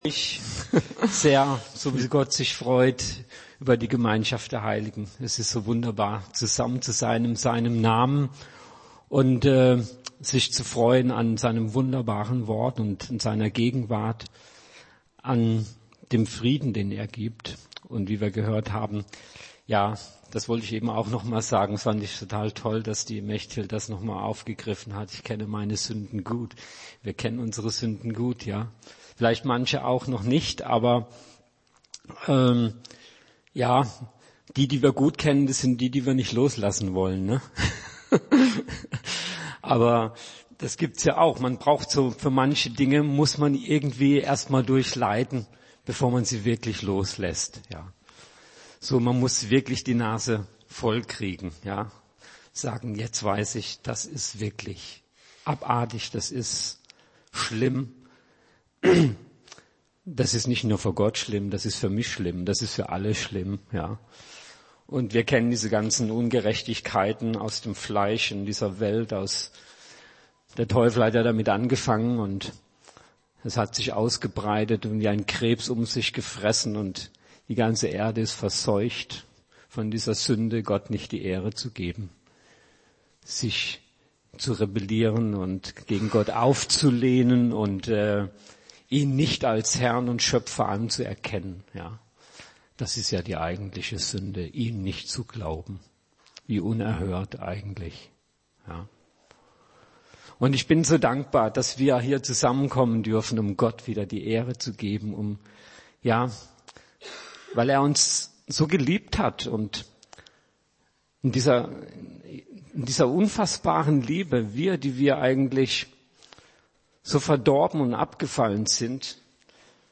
Predigt 28.01.2018